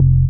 ORGAN-35.wav